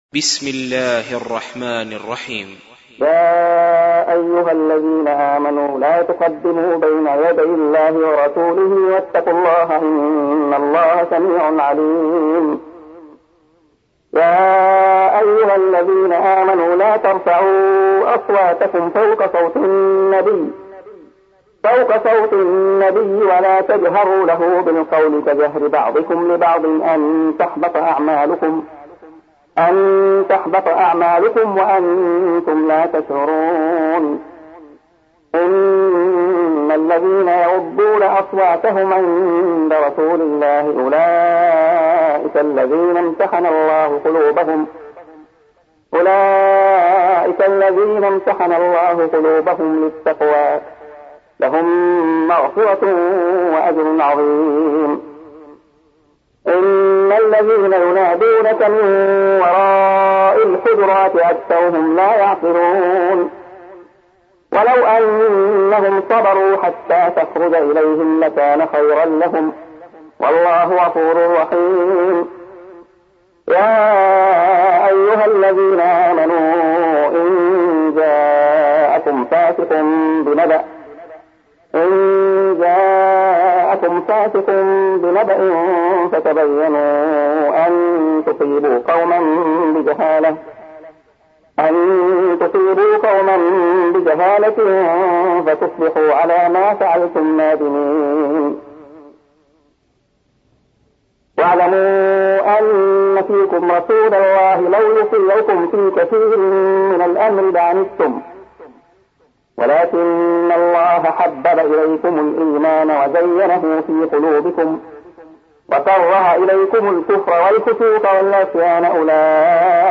سُورَةُ الحِجۡرِات بصوت الشيخ عبدالله الخياط